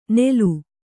♪ nelu